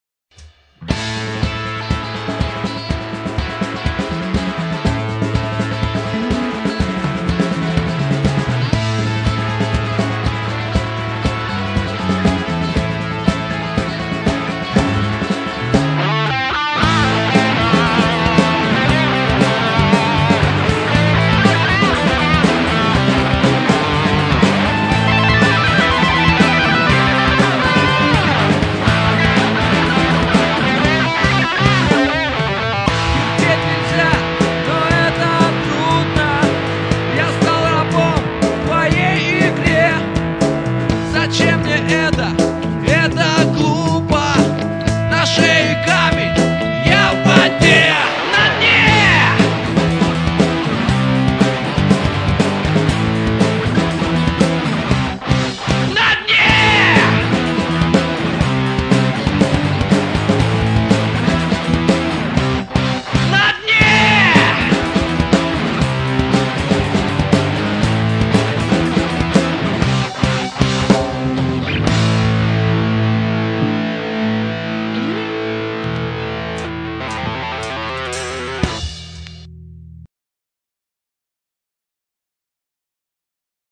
Тушите СВеТ - стиль: пост грандж-альтернативА
Называицца Навсегда с тоБОй.. (небольшой эксперемент.. немного панка).. послушайТе=) Жду коменты..